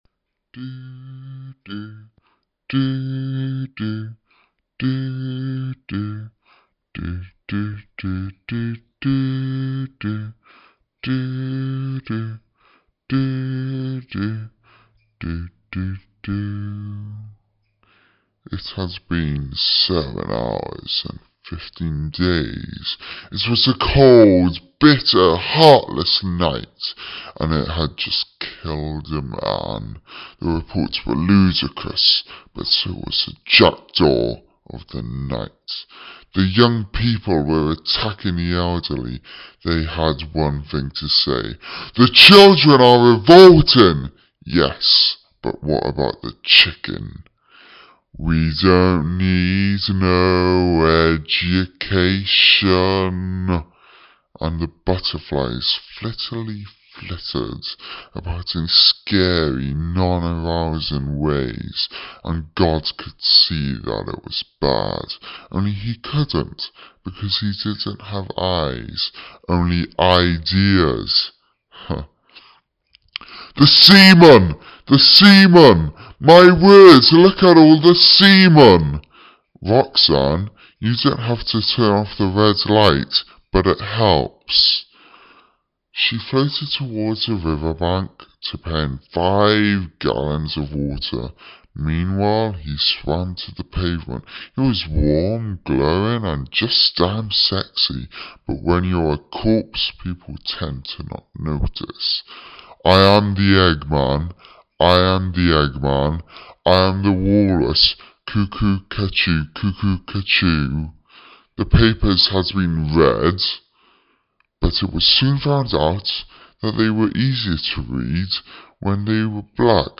Not only did I quote movies, songs, and books, I also added my own jerkbility, and came across as perfectly insane.